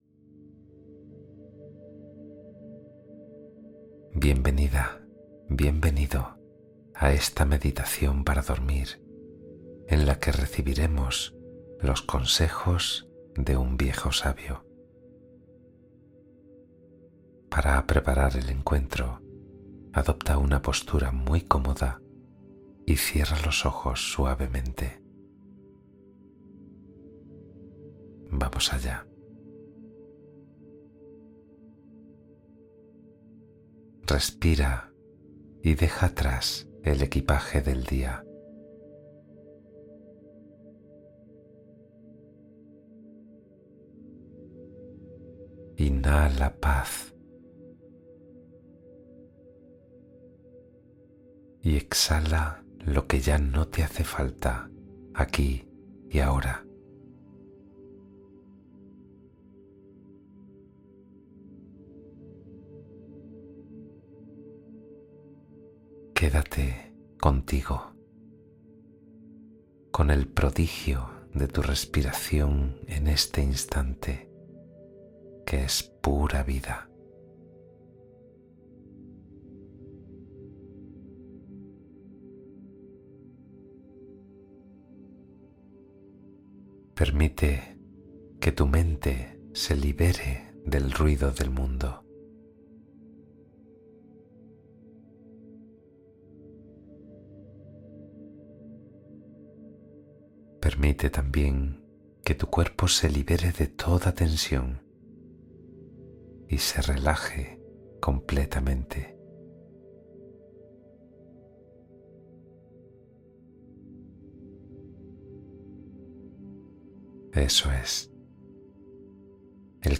Cuento Guiado con Meditación para Profundizar el Descanso